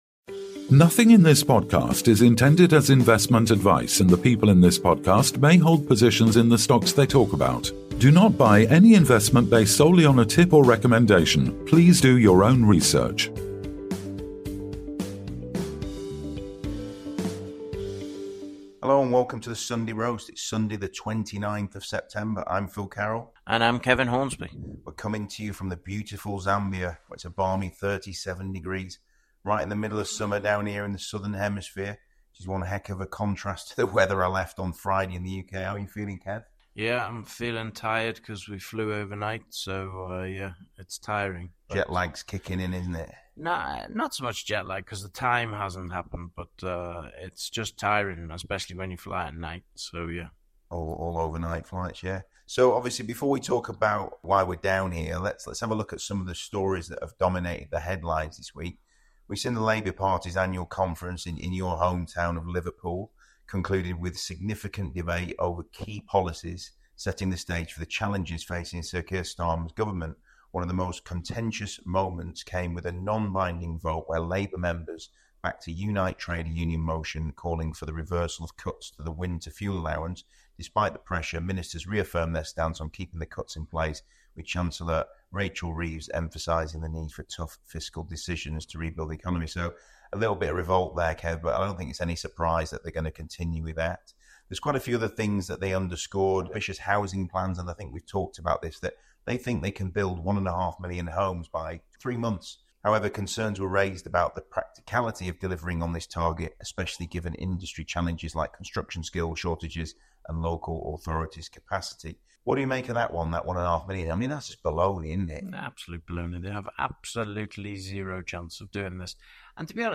Weekly News Roundup